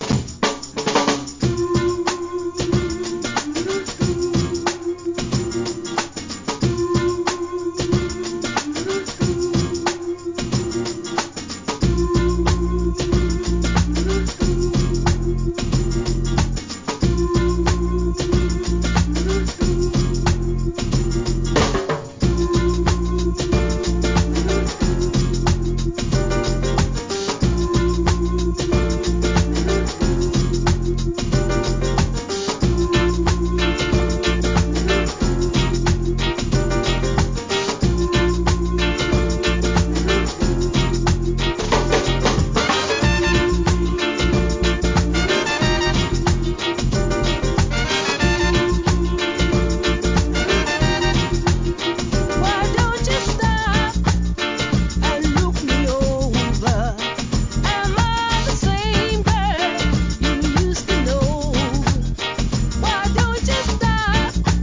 REGGAEナンバーB-1がオススメ!!